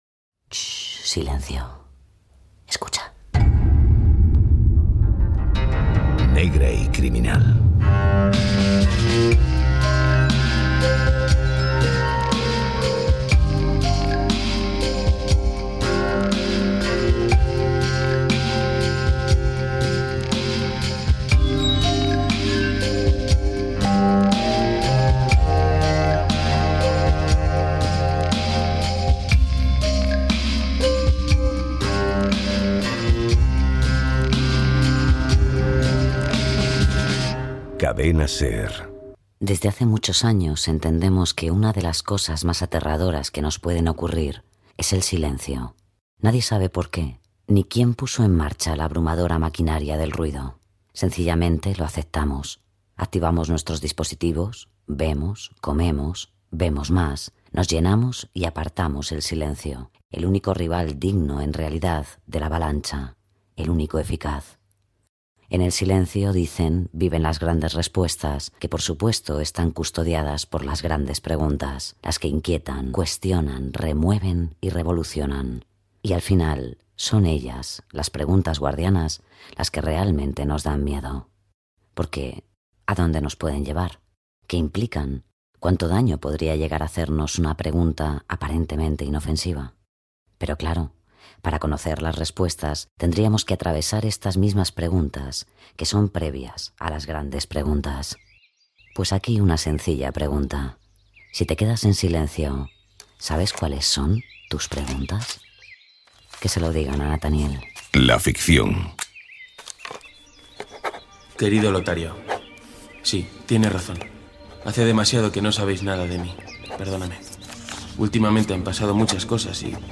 Adaptació de l'obra ‘El hombre de arena’ d'E.T.A.Hoffmann. Careta, presentació, el protagonista explica la seva situació, careta del programa, flaixbac de quan un venedor va trucar a la porta de la casa del protagonista
Ficció